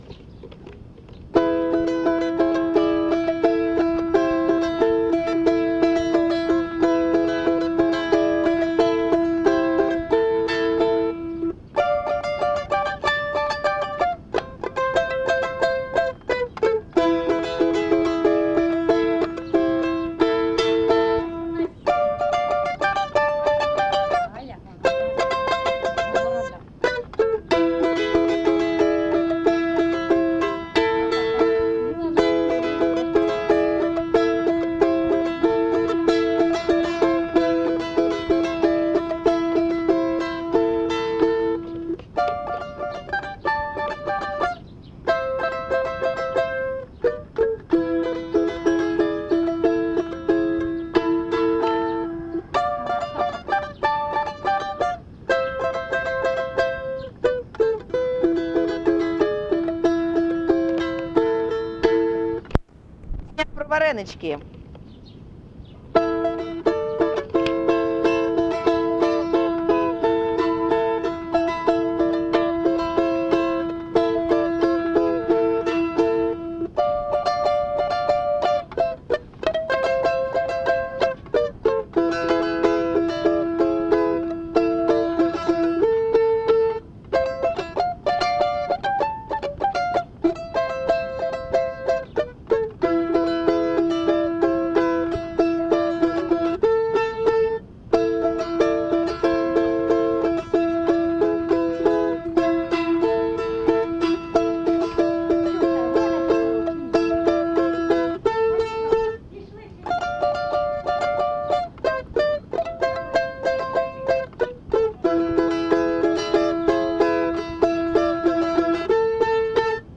ЖанрІнструментальна музика
балалайка